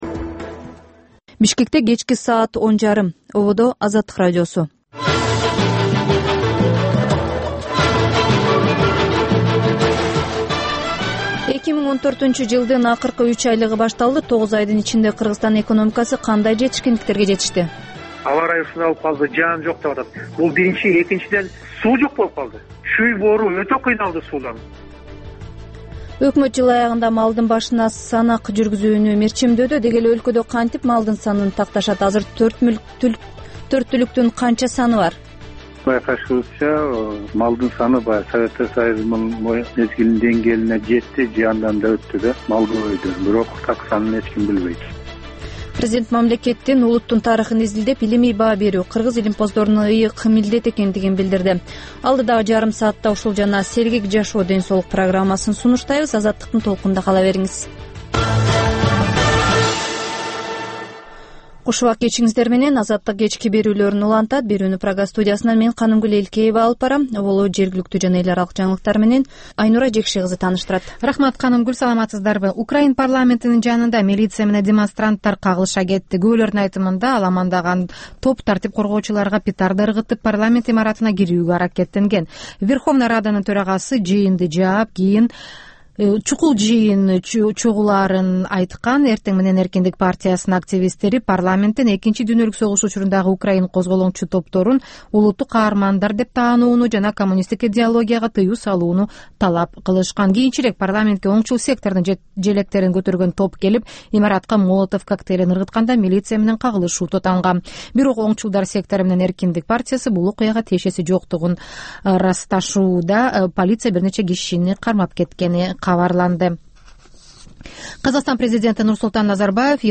"Азаттык үналгысынын" бул кечки жарым сааттык экинчи берүүсүнүн кайталоосу «Арай көз чарай» түрмөгүнүн алкагындагы тегерек үстөл баарлашуусу, репортаж, маек, талкуу, аналитикалык баян, сереп, угармандардын ой-пикирлери, окурмандардын э-кат аркылуу келген пикирлеринин жалпыламасы жана башка берүүлөрдөн турат.Ар күнү Бишкек убакыты боюнча саат 22:30дан 23:00га чейин кайталанат.